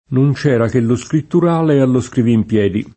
non © $ra ke llo Skrittur#le allo SkrivimpL$di] (Cicognani)